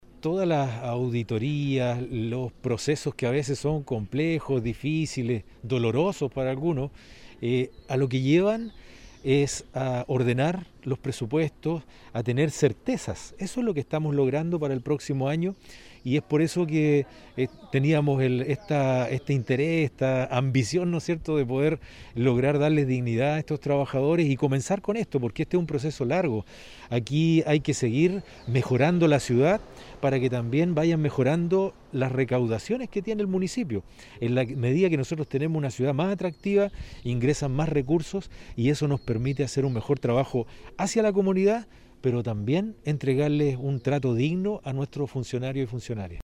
Comentario 2 Armando Flores Jiménez – Alcalde de la comuna
CUÑA-4-ALCALDE-FLORES.mp3